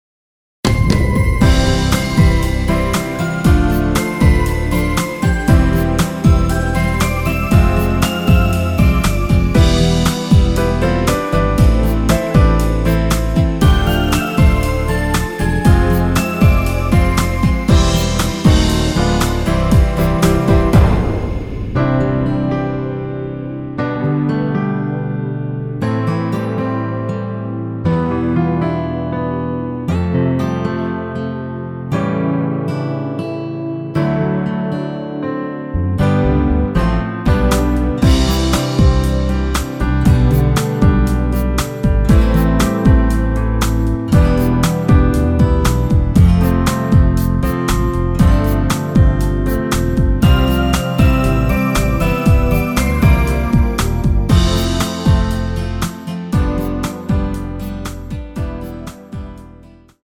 축가에 잘 어울리는 곡 입니다.
◈ 곡명 옆 (-1)은 반음 내림, (+1)은 반음 올림 입니다.
앞부분30초, 뒷부분30초씩 편집해서 올려 드리고 있습니다.